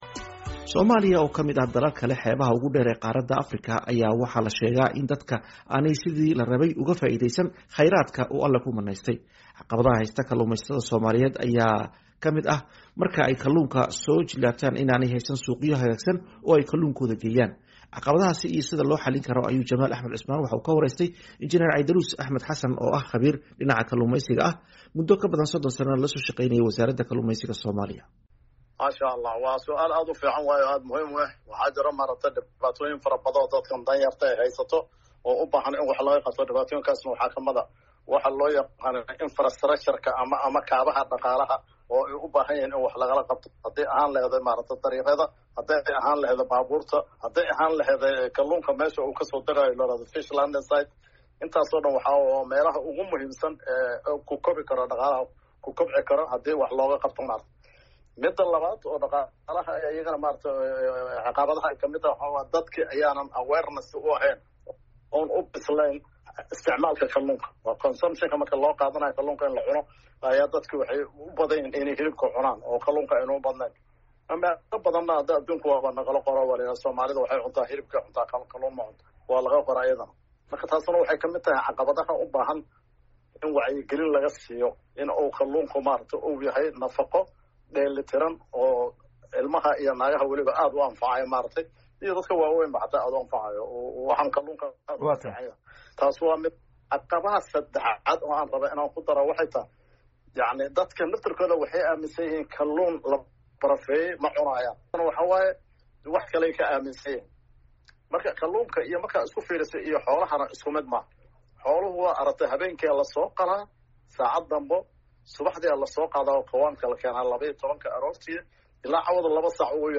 ka wareystay